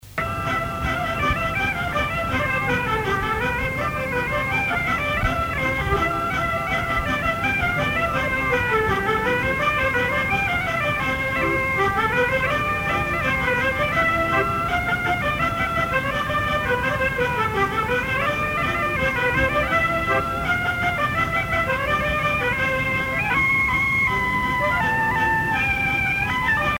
Scottish
danse : scottich trois pas
Pièce musicale éditée